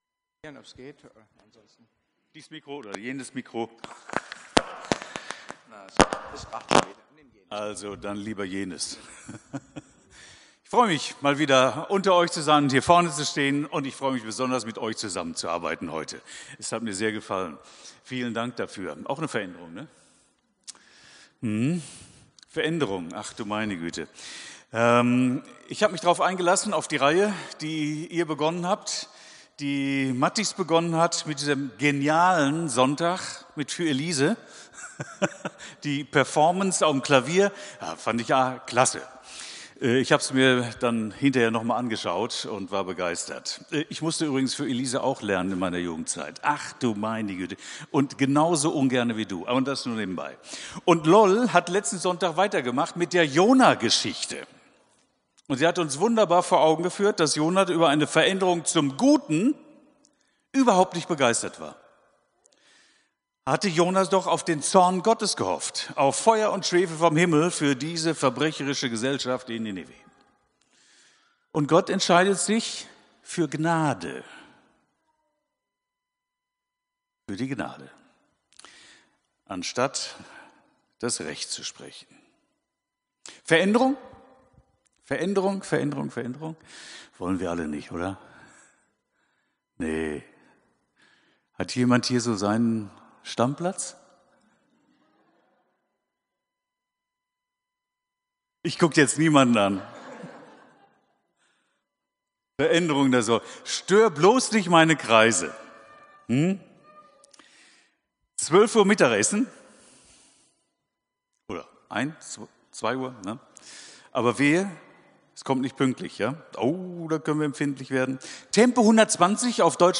Predigt vom 16.03.2025